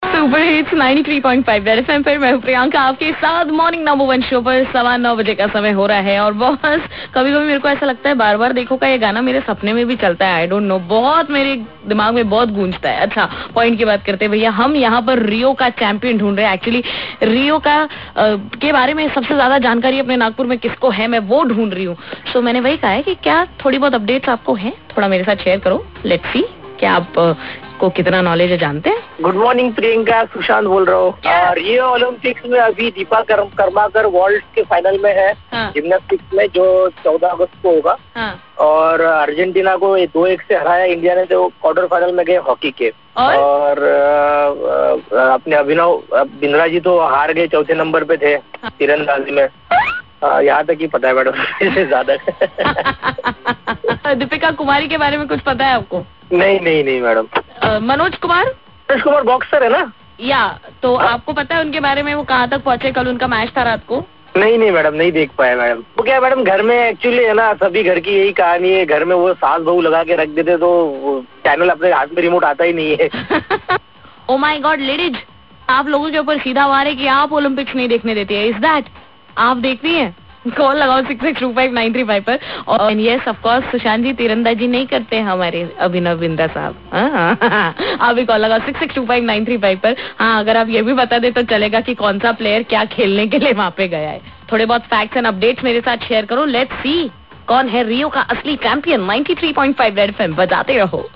talking caller